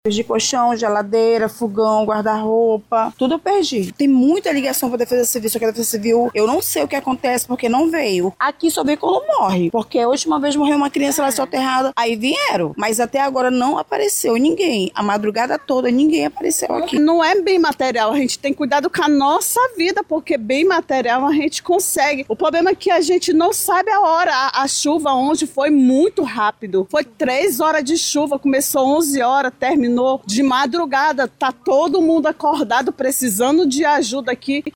Elas relataram a dificuldade em conseguir assistência por parte da Defesa Civil Municipal.